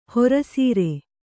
♪ hora sīre